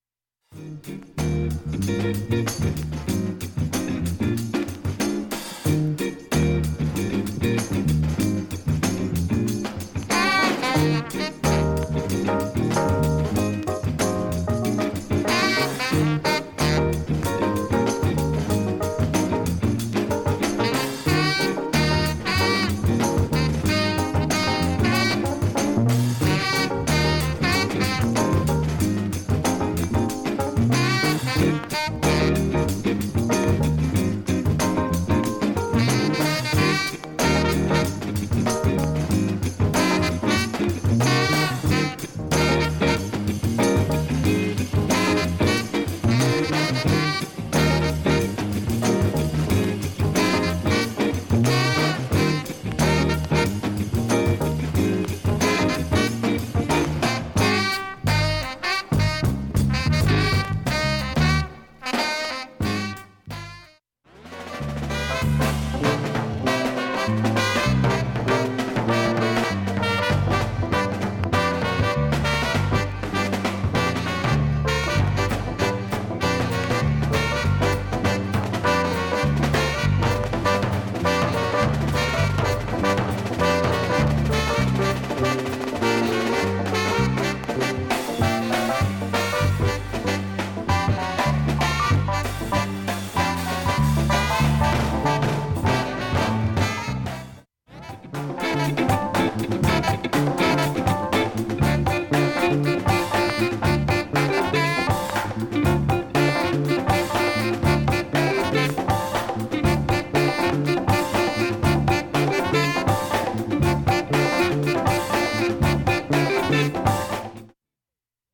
ありません曲間もチリはほぼありません。
音圧音質良好全曲試聴済み。
A-2中盤にかすかなプツが１１回出ます。
イナタいジャズ・ファンクカヴァー